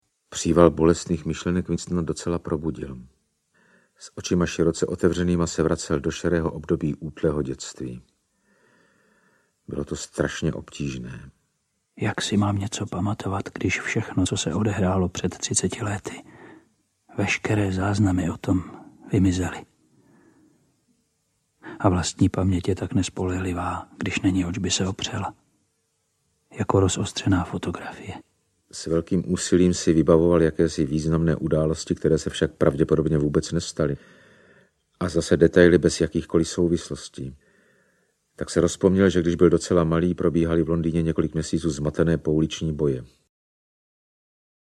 1984 audiokniha
Ukázka z knihy